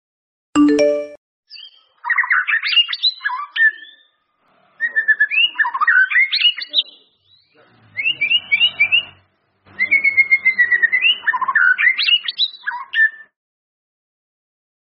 Nada dering suara Burung Murai Batu
Keterangan: Unduh nada dering suara Burung Murai Batu.
nada-dering-suara-burung-murai-batu-id-www_tiengdong_com.mp3